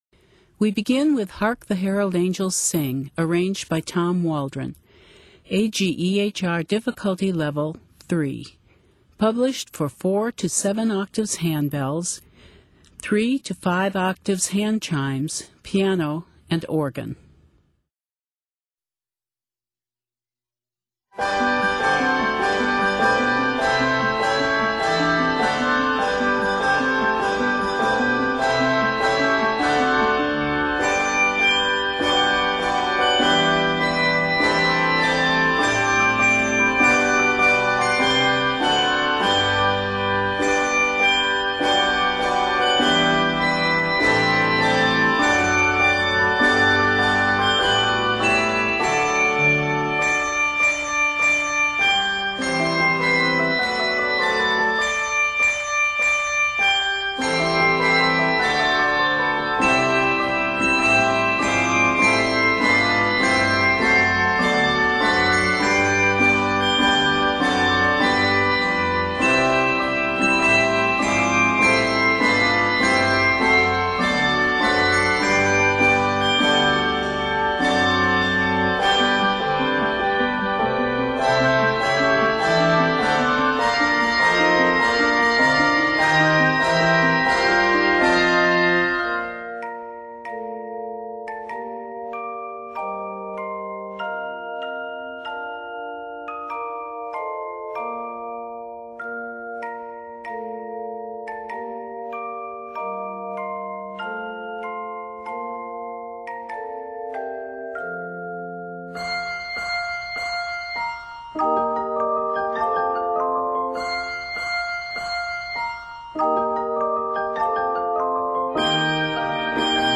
A solid arrangement of the jubilant carol